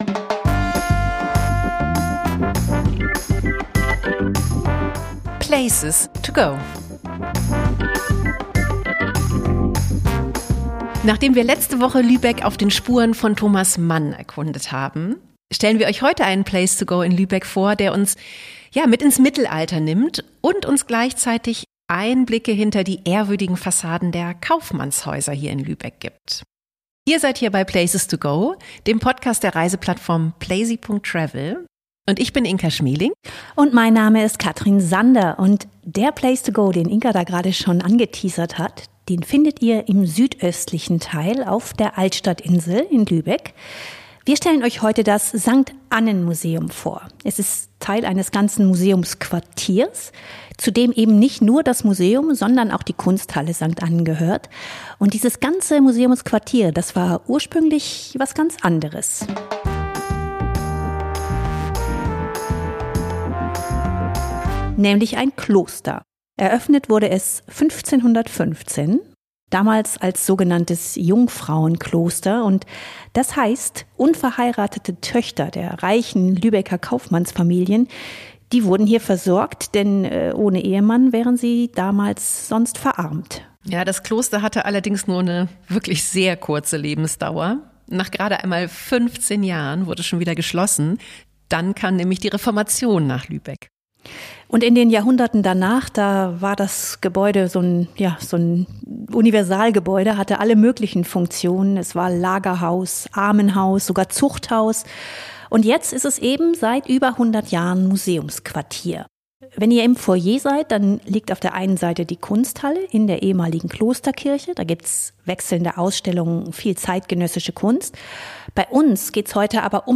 Interview-Gast